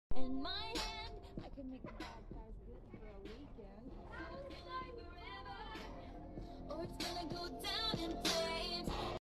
blasting music in the balconyyy sound effects free download